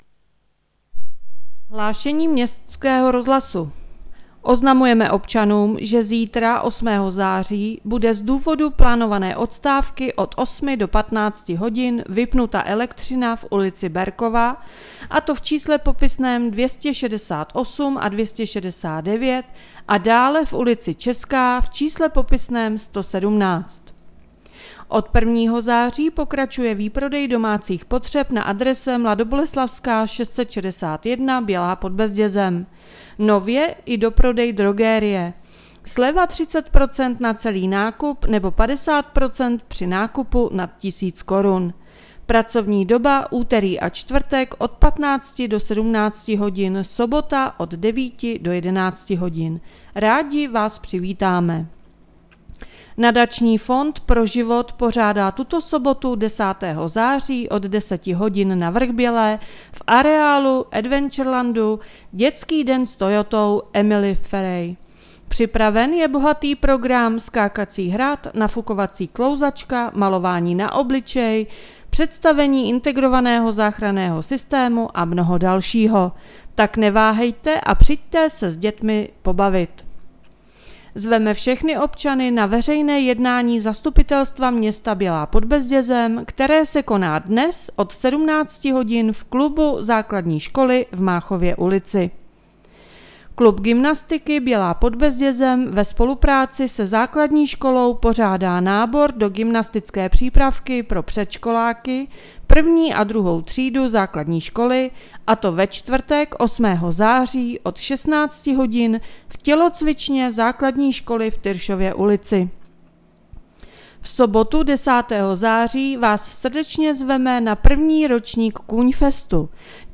Hlášení městského rozhlasu 7.9.2022